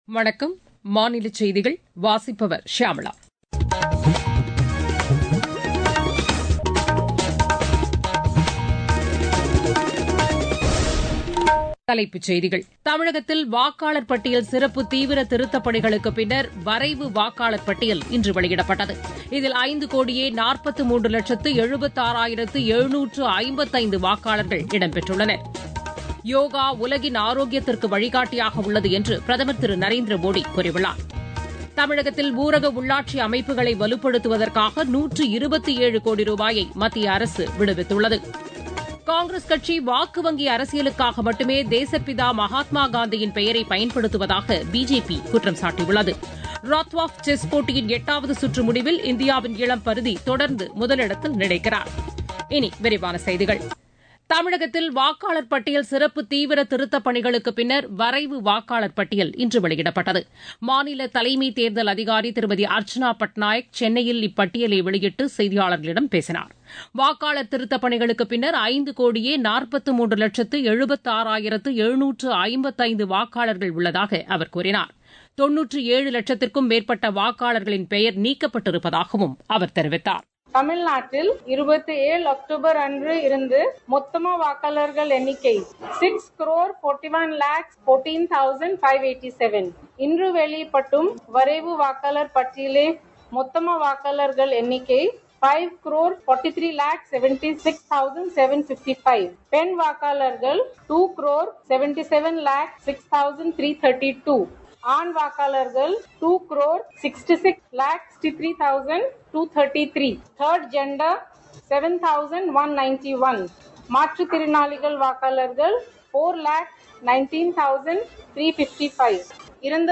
Transcript summary Play Audio Morning News